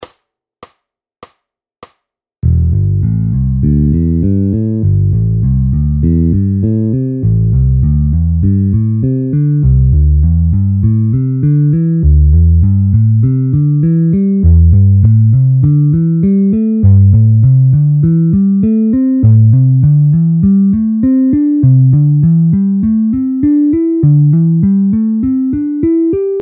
ZVUKOVÁ UKÁZKA  stupnice pentatonická C dur
01-C dur pentatonická.mp3